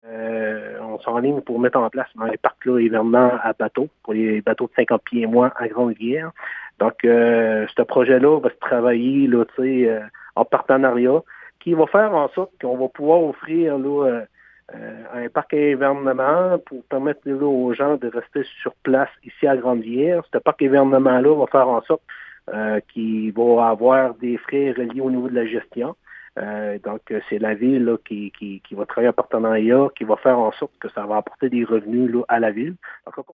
Le maire, Gino Cyr, donne des explications.